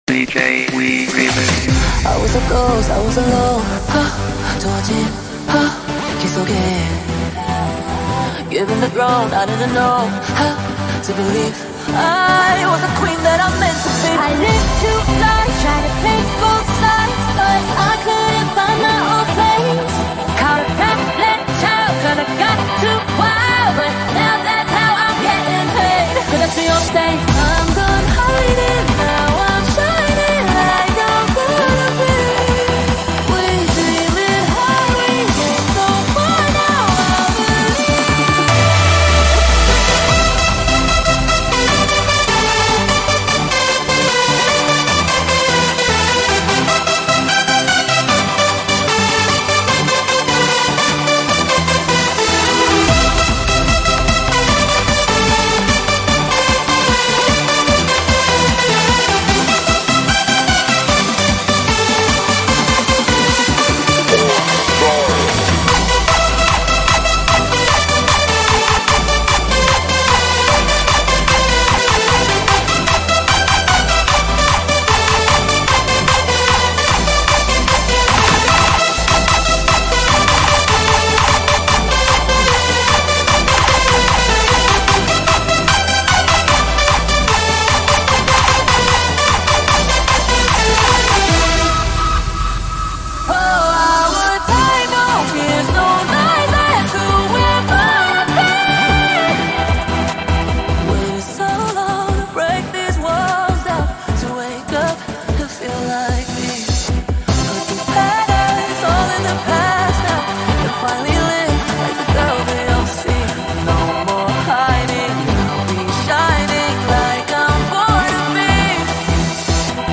極震撼音浪 Bass Hardstyle Remix